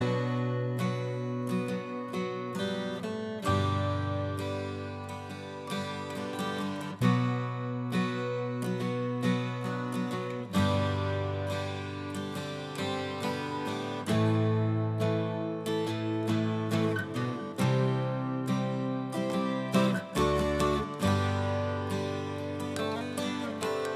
Minus Guitar Solo Rock 5:39 Buy £1.50